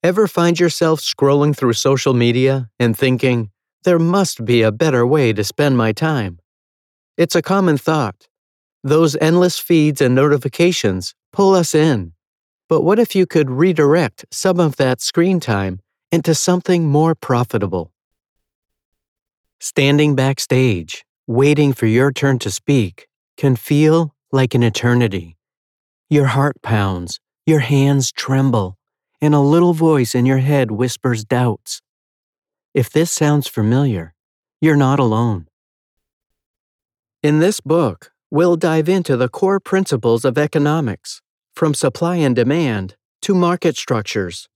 My voice style runs the gamut from "regular guy" to "empathetic" to "voice of authority".
Geo English (North American) Adult (30-50) | Older Sound (50+) My voice style runs the gamut from "regular guy" to "empathetic" to "voice of authority".